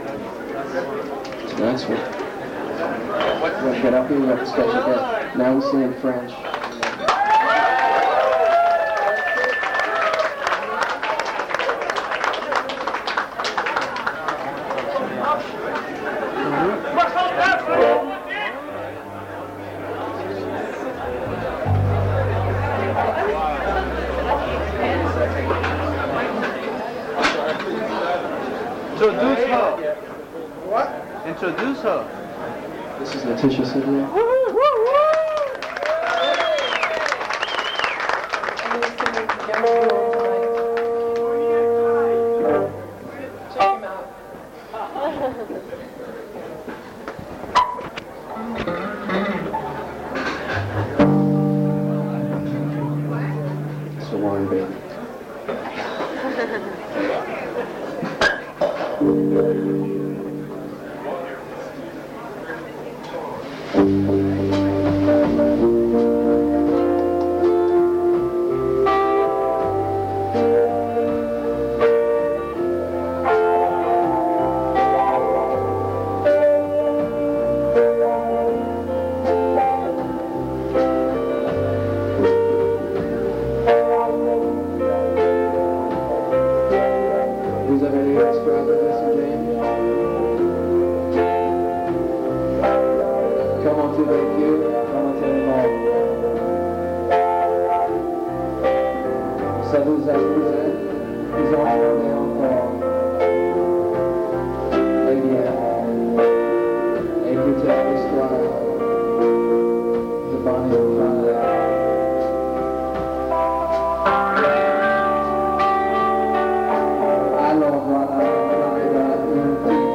The Borderline, London
You can hear the whole show over on YouTube (audio only).